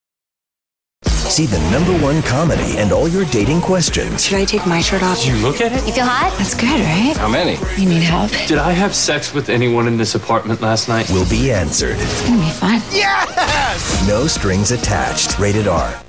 No Strings Attached TV Spots